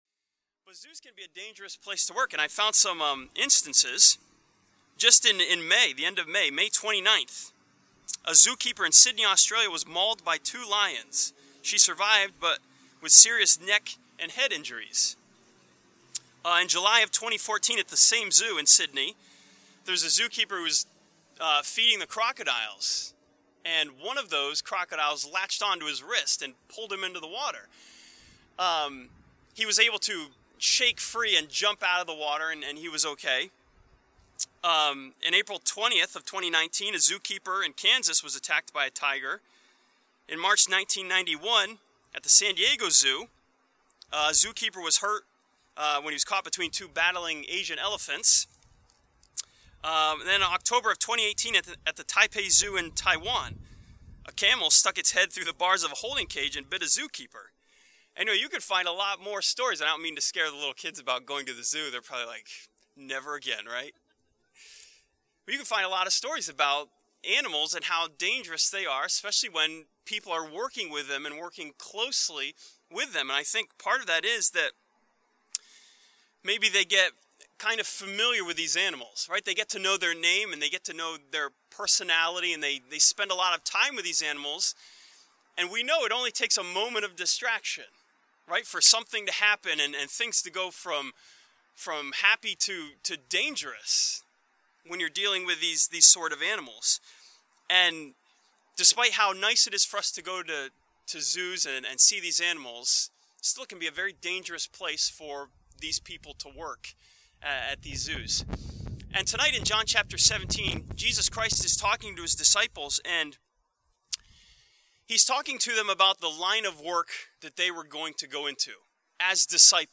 This sermon from John chapter 17 studies the dangerous job Christians have and the importance of sanctification and trusting Jesus.